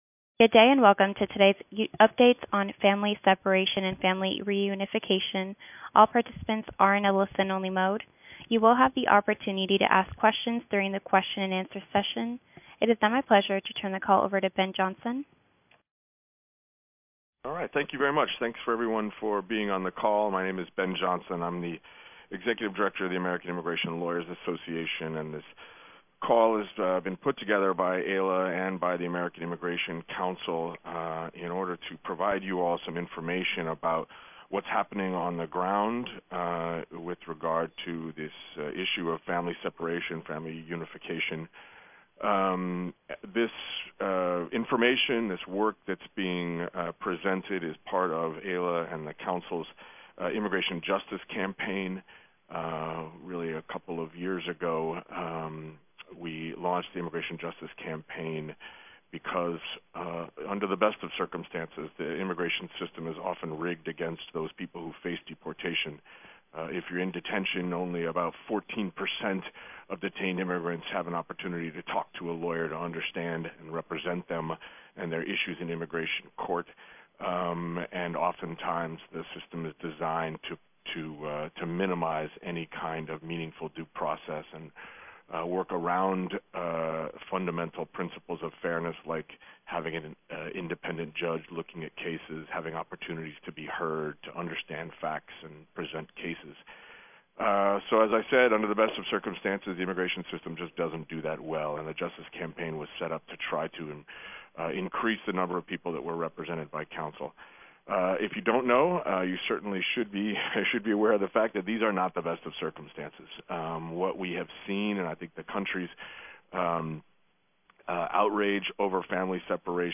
Audio from Telebriefing on Family Separation and Family Reunifications
On a press call on Tuesday, July 24, 2018, representatives from AILA, the American Immigration Council, the Immigration Justice Campaign, and the Dilley Pro Bono Project provided updates on what’s happening to separated parents detained in and around El Paso, Texas, and the latest from the family detention center in Dilley, Texas.